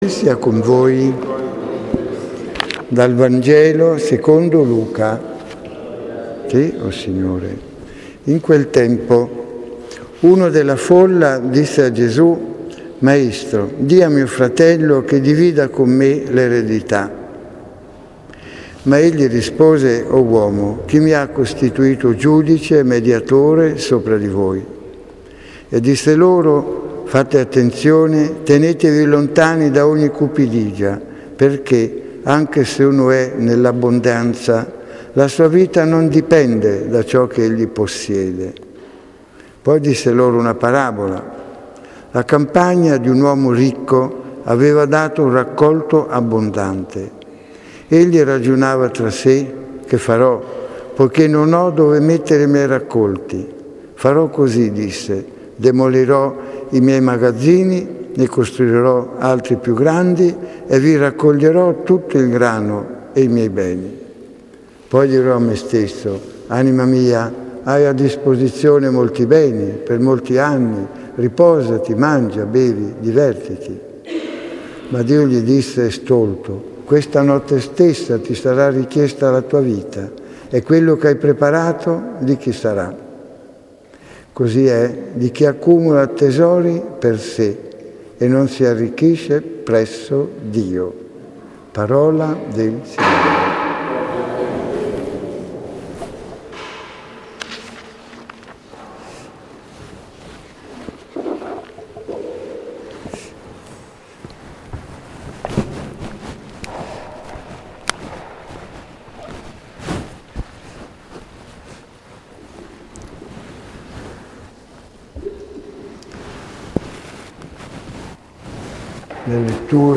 Clicca sul link con il simbolo mp3 per ascoltare l'omelia di Domenica 28.08.2016 XXII T.O. /C